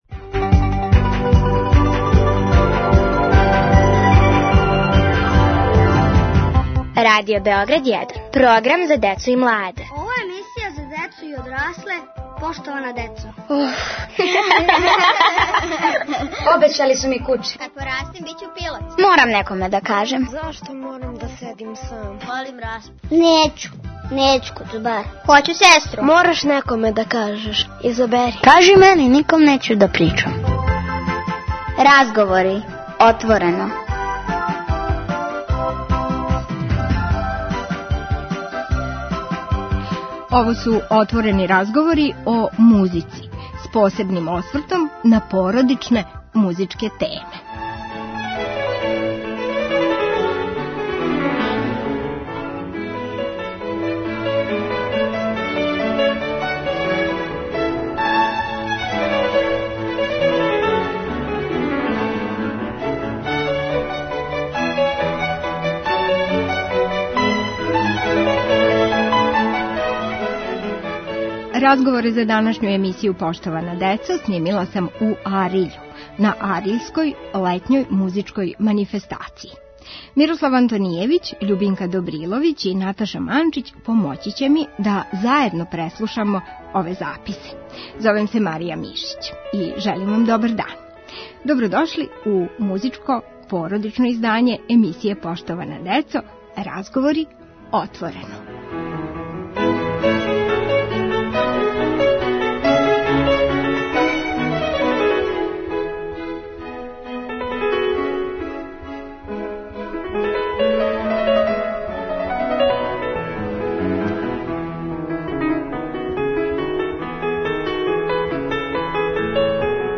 Поштована деца, окупљена у летњем мастер класу на АРЛЕММ-у, отворено разговарају о својим породицама и њиховим музичким склоностима.